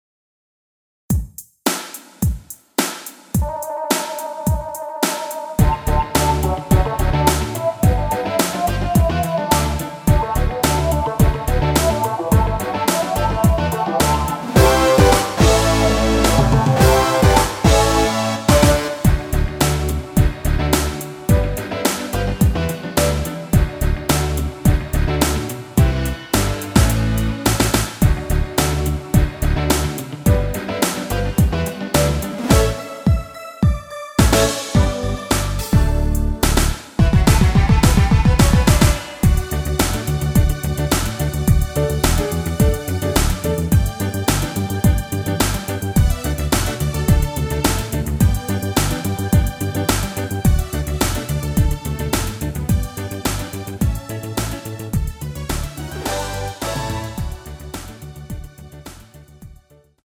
원키에서(-2)내린 MR입니다.
D
◈ 곡명 옆 (-1)은 반음 내림, (+1)은 반음 올림 입니다.
앞부분30초, 뒷부분30초씩 편집해서 올려 드리고 있습니다.